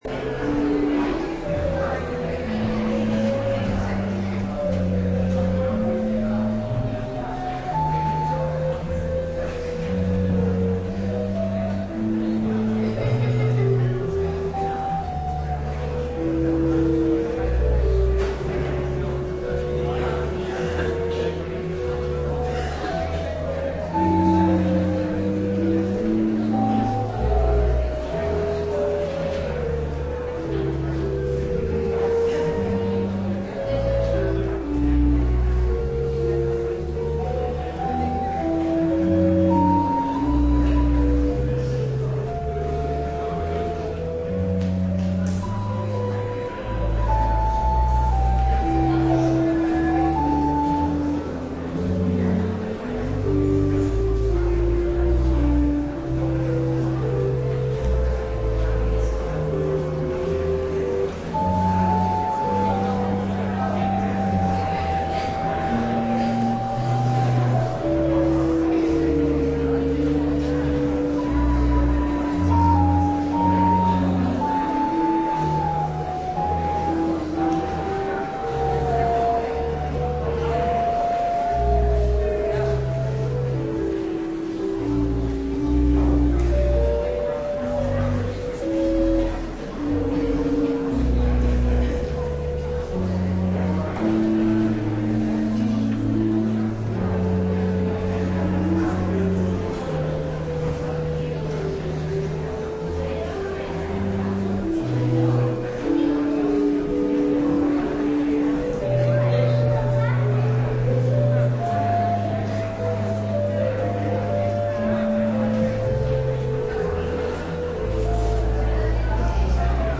Preek over Genesis 4:13-15 op zondagavond 2 februari 2020 (voortzetting/dankzegging Heilig Avondmaal) - Pauluskerk Gouda